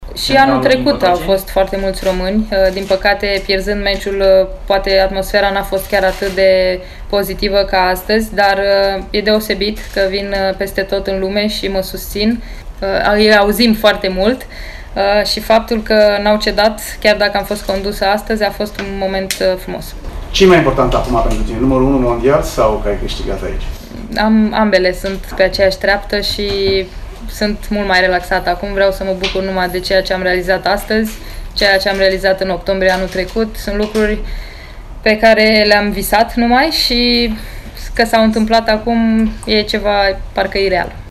Simona a vorbit după triumful de la Paris şi despre susţinerea din tribune, la Roland Garros: